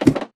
ladder3.ogg